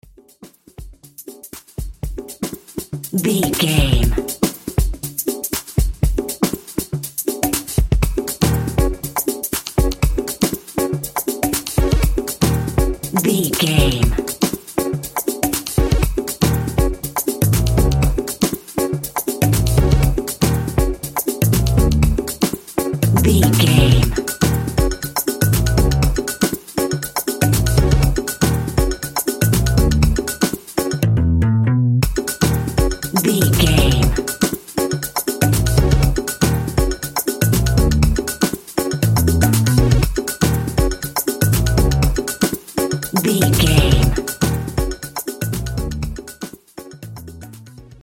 Aeolian/Minor
E♭
Drum and bass
break beat
electronic
sub bass
synth
jazz drums
jazz bass
jazz guitar
jazz piano